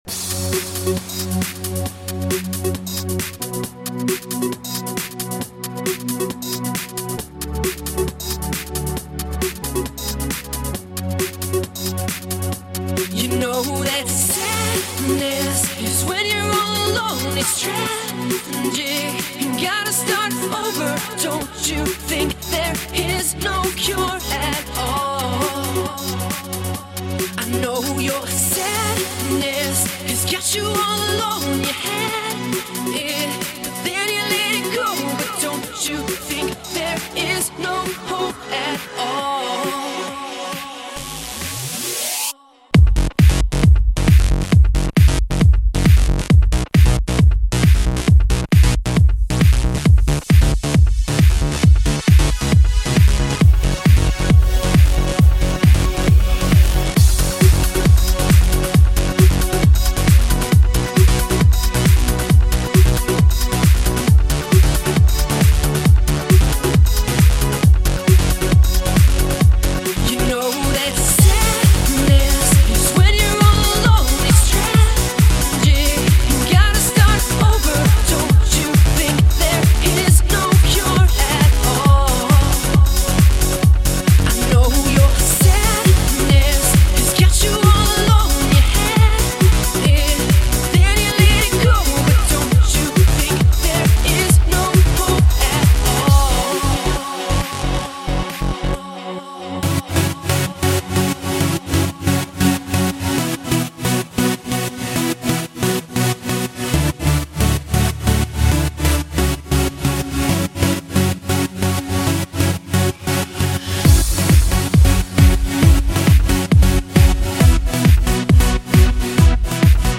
Жанр:Club/Dance